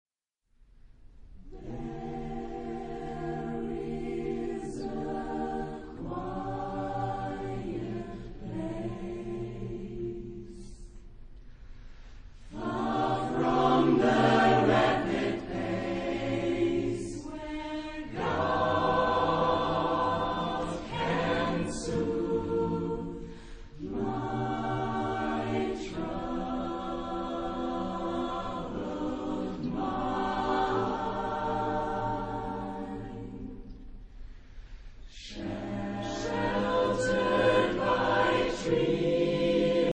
Type de choeur : SATB  (4 voix mixtes )